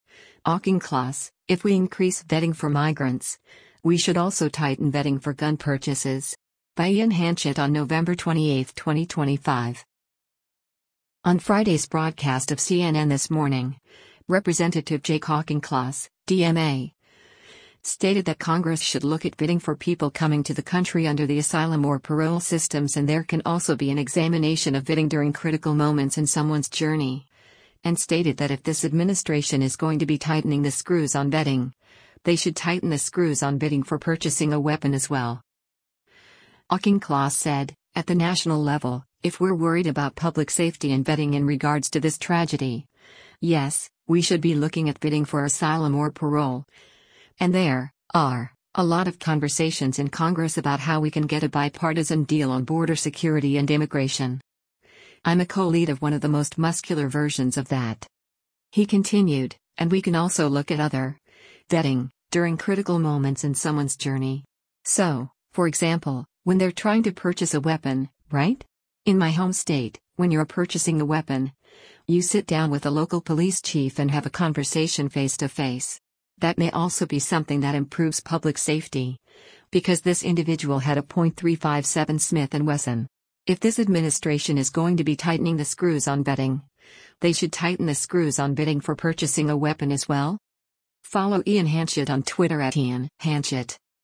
On Friday’s broadcast of “CNN This Morning,” Rep. Jake Auchincloss (D-MA) stated that Congress should look at vetting for people coming to the country under the asylum or parole systems and there can also be an examination of vetting “during critical moments in someone’s journey.” And stated that “If this administration is going to be tightening the screws on vetting, they should tighten the screws on vetting for purchasing a weapon as well.”